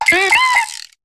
Cri de Keunotor dans Pokémon HOME.